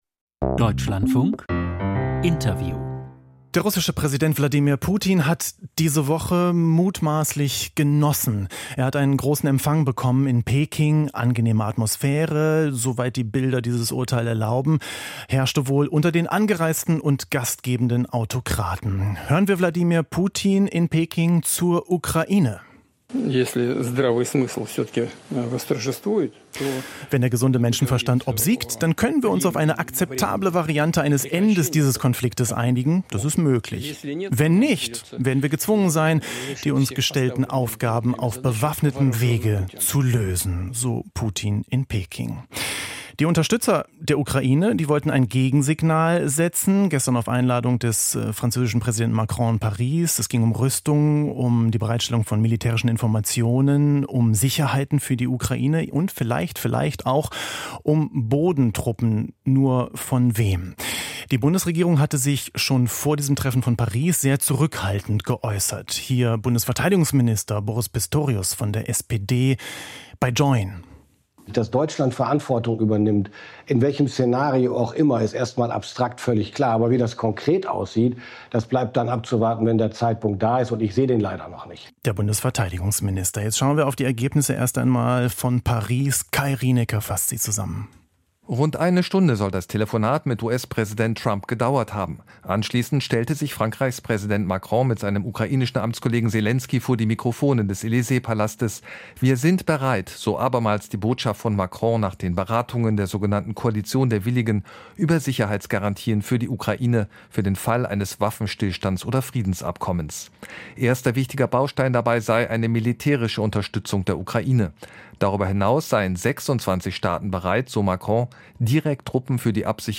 Sicherheitsgarantien für Ukraine? - Interview Roderich Kiesewetter, CDU-MdB